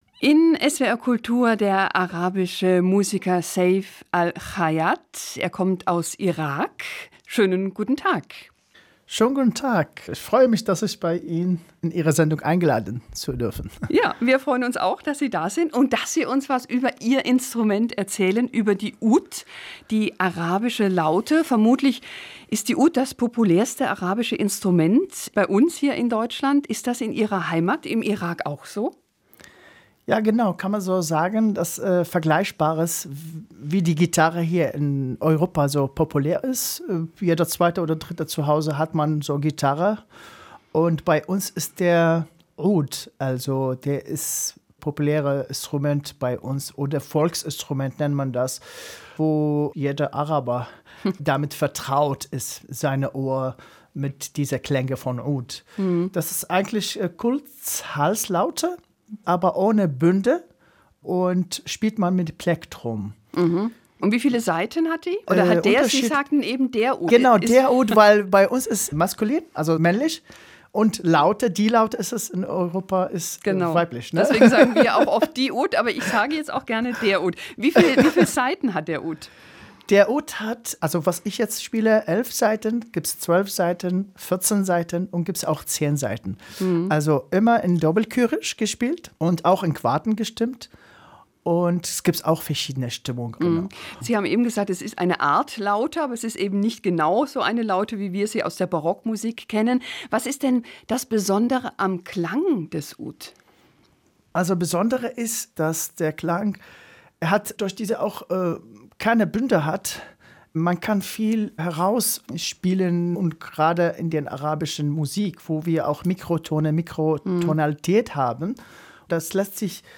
Musikgespräch